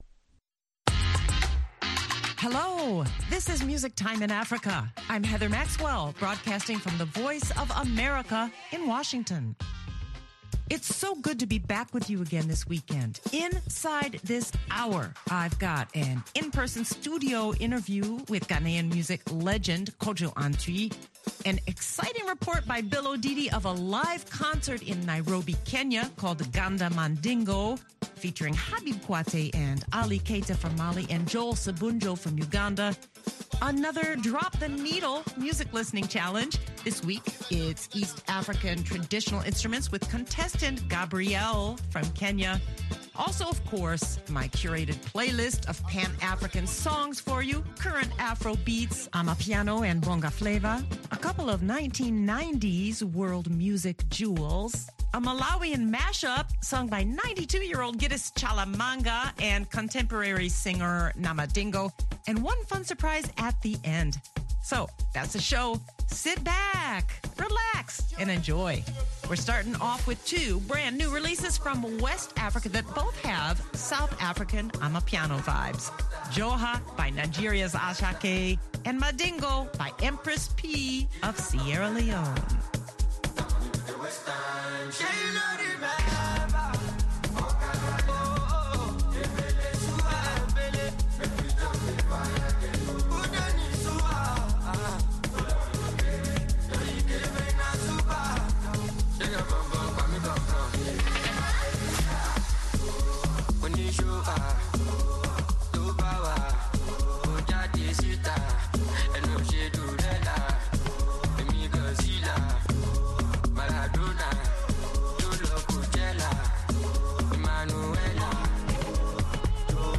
the playlist includes current Afrobeats, Amapiano and BongaFlava, a couple of 1990s World Music jewels